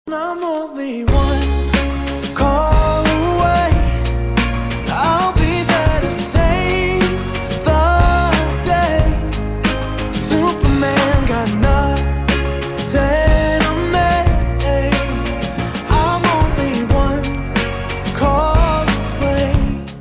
Kategorie POP